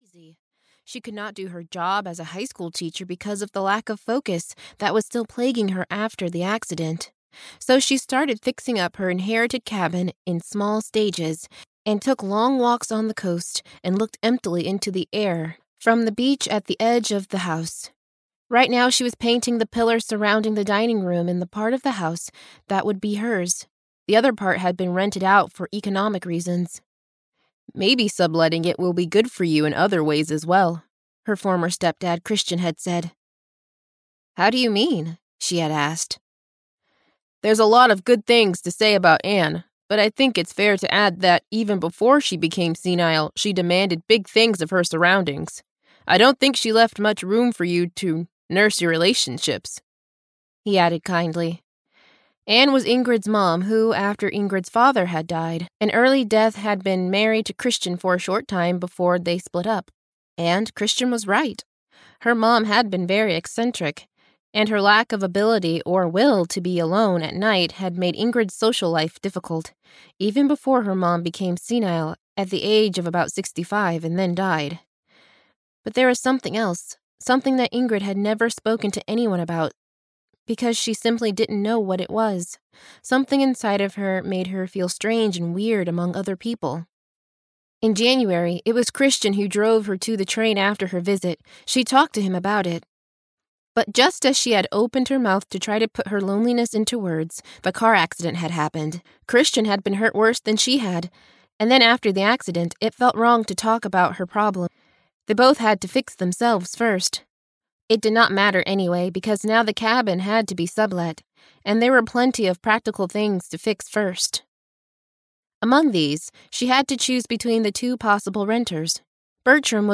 Audio knihaCabin Fever 4: Painting a Picture (EN)
Ukázka z knihy